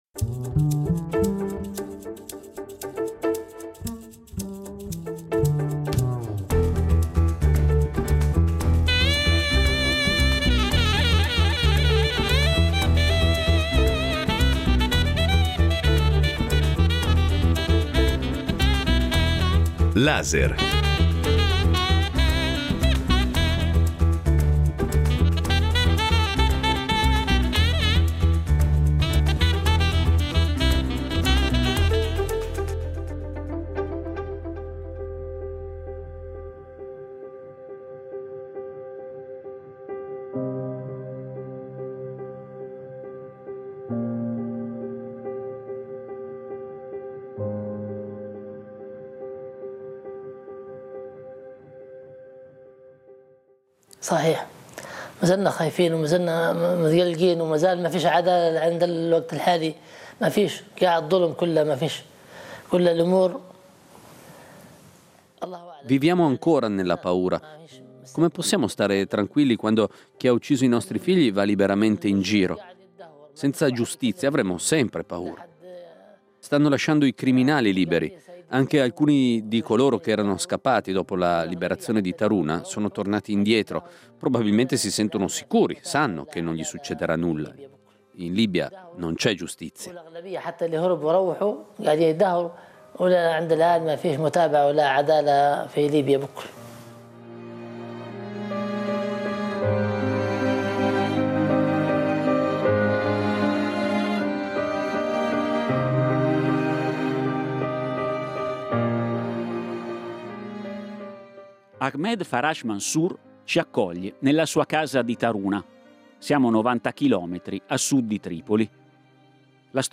Come succede spesso in situazioni di conflitto o post-conflitto, l'impunità rischia di diventare il principale ostacolo alla pace e alla riconciliazione. In questo radio documentario vittime di violazioni dei diritti umani e operatori di giustizia descrivono alla perfezione la sfida, difficilissima, che si trova di fronte il paese nel tentativo di voltare pagina.